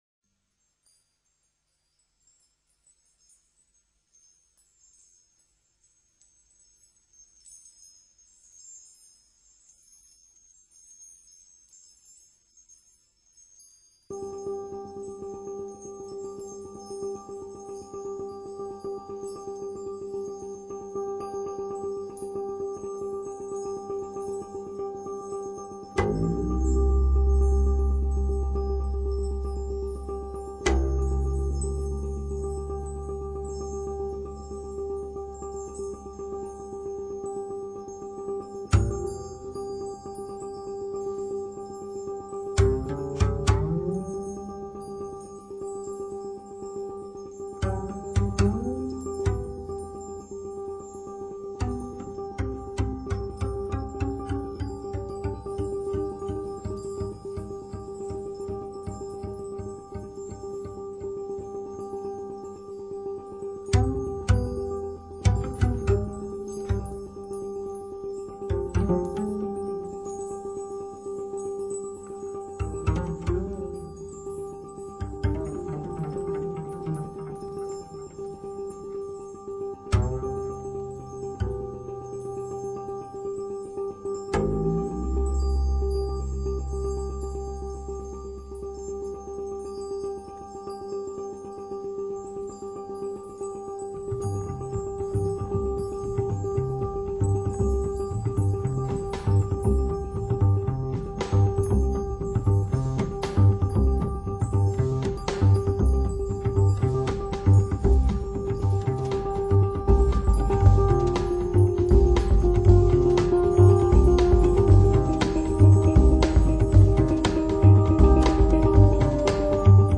Here’s an instrument you may not have heard of: the Hang.
2. The great bassline that kicks in at 1:36.
Tags2000s 2009 Britain Jazz